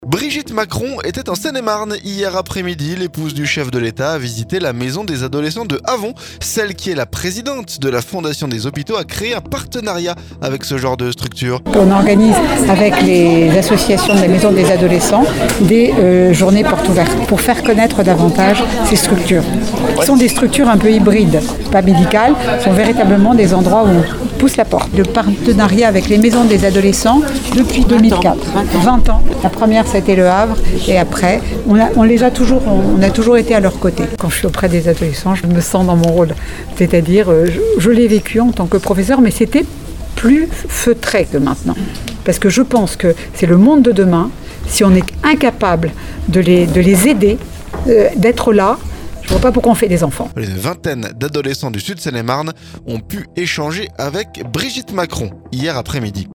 Celle qui est présidente de la fondation des hôpitaux a créé un partenariat avec ce genre de structures. Elle a répondu à Oxygène, la radio de la Seine-et-marne.